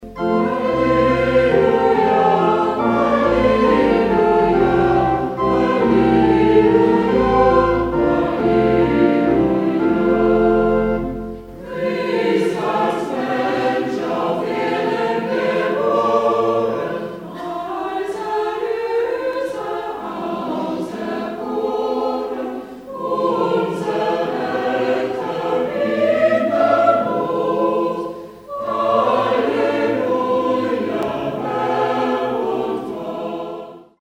Ruf und Chorvers - mit Gemeinde